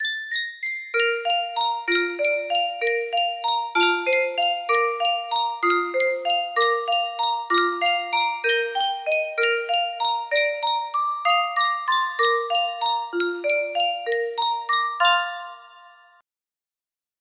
Sankyo Music Box - Lift Every Voice and Sing FFH music box melody
Full range 60